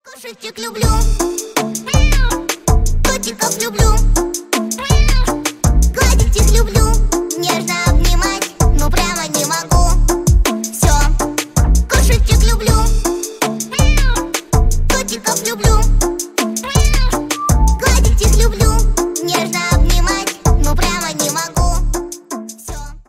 Поп Музыка
пародия